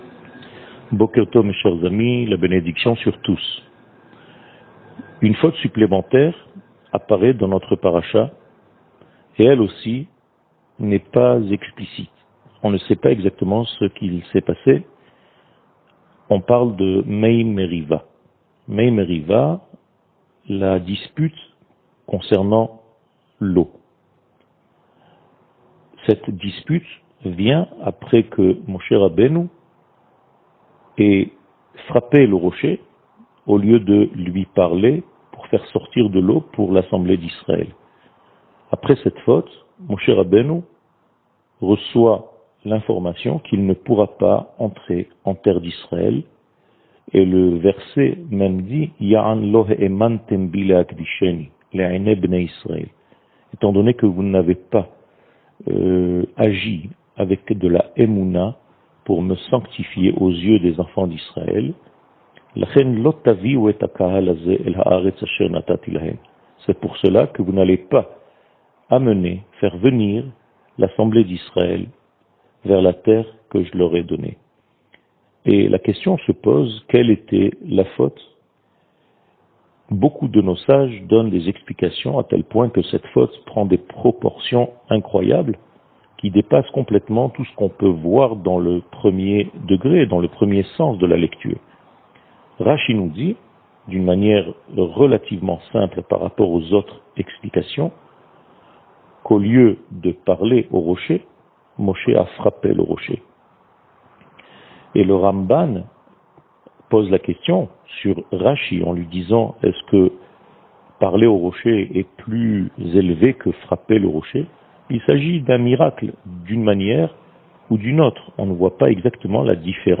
שיעור מ 13 יוני 2021